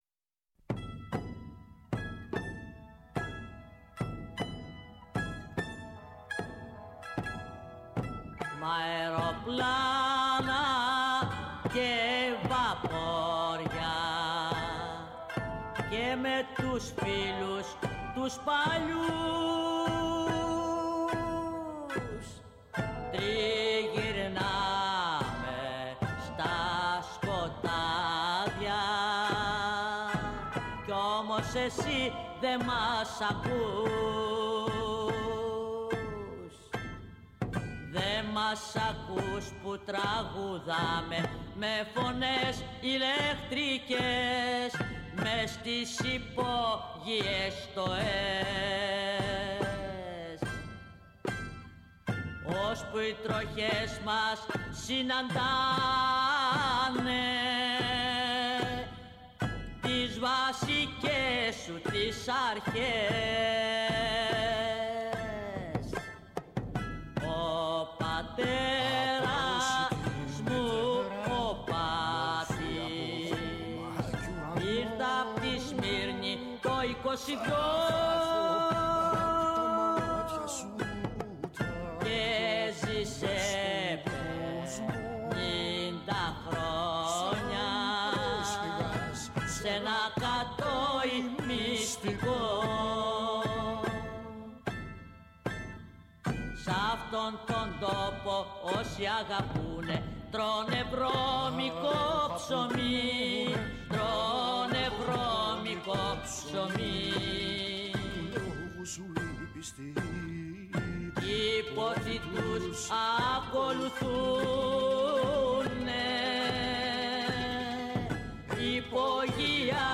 Στην εκπομπή ακούστηκαν χαρακτηριστικά παραδοσιακά τραγούδια.